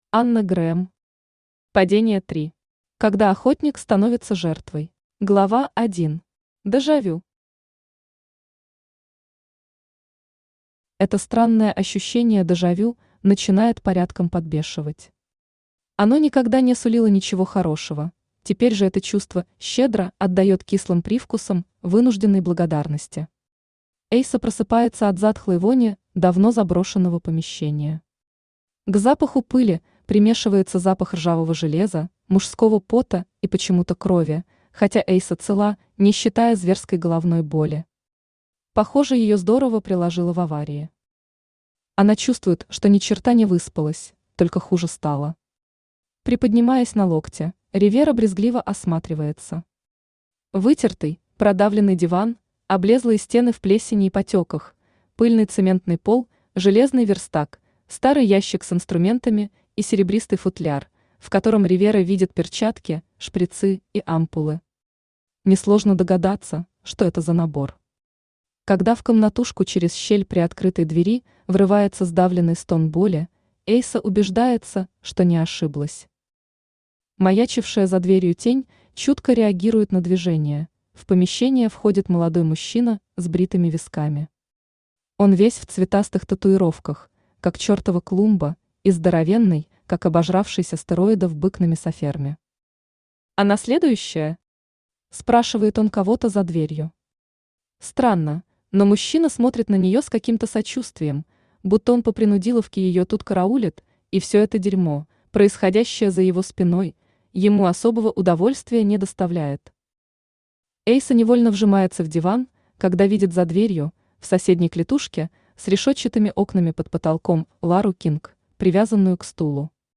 Аудиокнига Падение 3. Когда охотник становится жертвой | Библиотека аудиокниг
Когда охотник становится жертвой Автор Анна Грэм Читает аудиокнигу Авточтец ЛитРес.